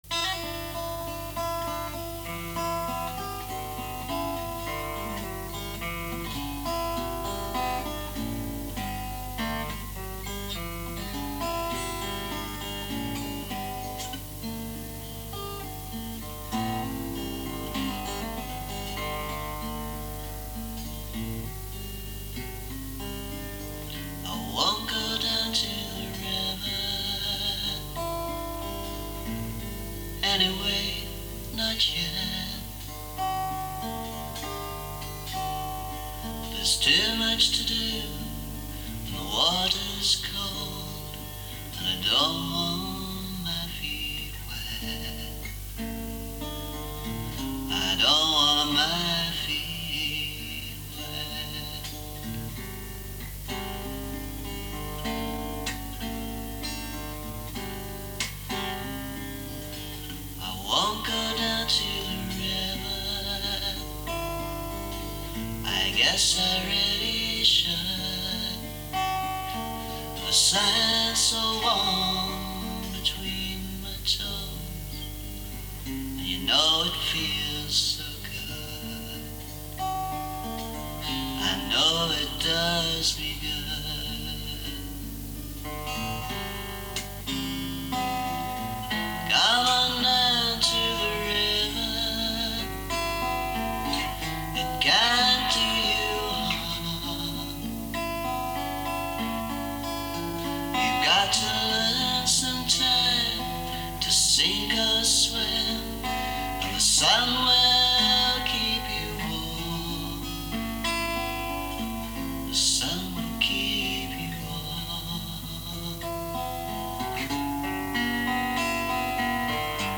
Recorded on domestic equipment in the 1980s, so not commercial quality. But not a bad version vocally.